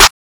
Clap (Grammys).wav